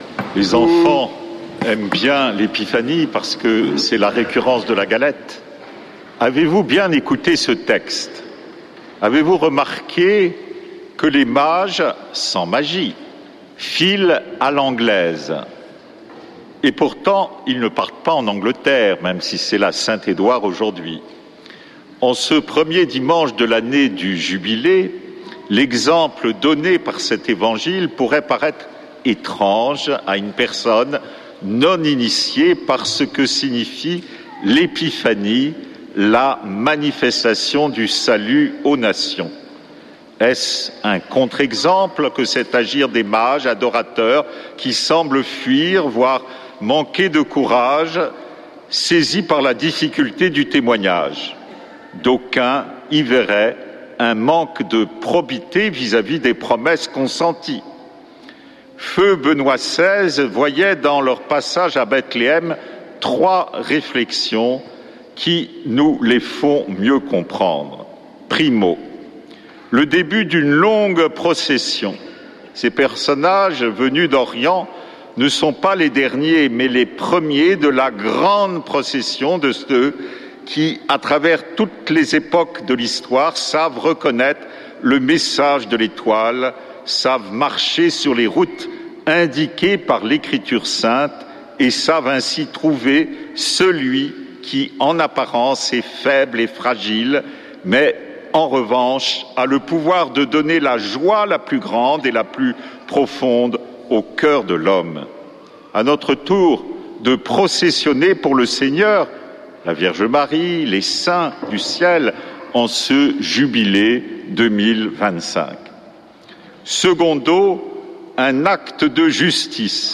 dimanche 5 janvier 2025 Messe depuis le couvent des Dominicains de Toulouse Durée 01 h 30 min